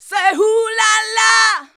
SAY 1.wav